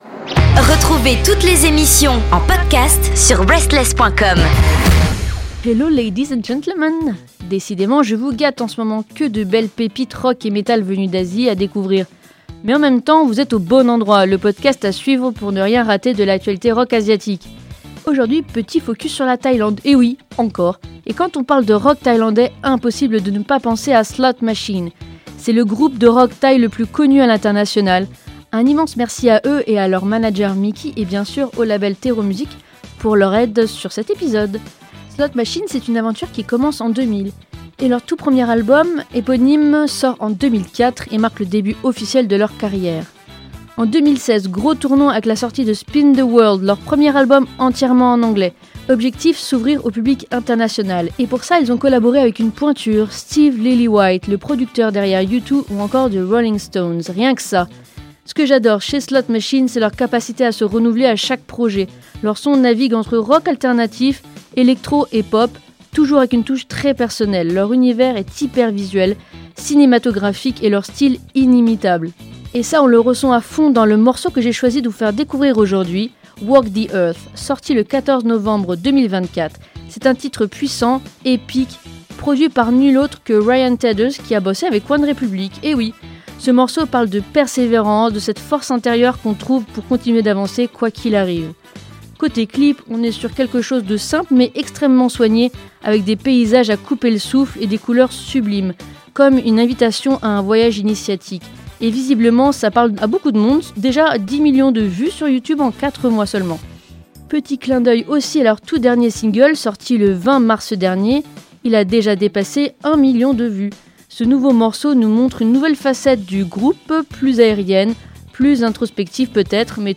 Leur son est un mix électrisant entre Depeche Mode et Muse — un rock planant, puissant, un peu rétro-futuriste, qui vous emmène loin, très loin… jusqu’au pays du sourire.